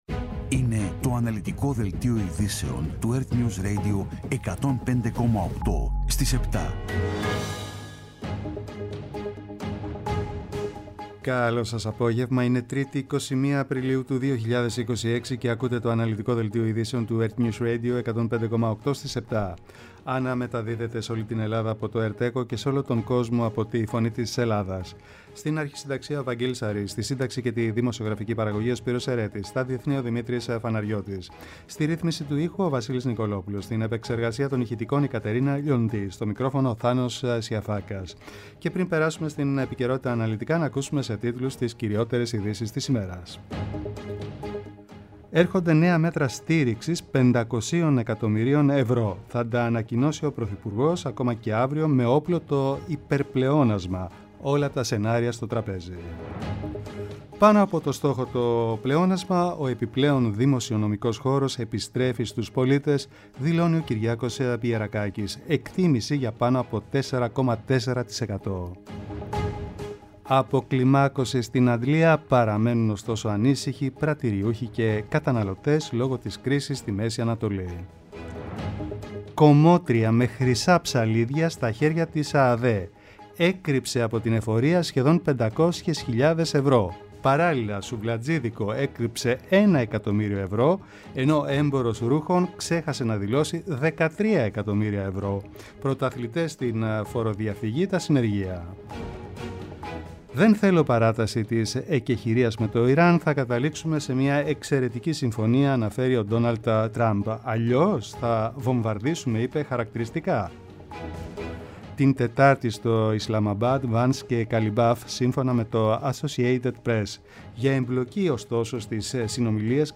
Το αναλυτικό ενημερωτικό μαγκαζίνο στις 19:00. Με το μεγαλύτερο δίκτυο ανταποκριτών σε όλη τη χώρα, αναλυτικά ρεπορτάζ και συνεντεύξεις επικαιρότητας.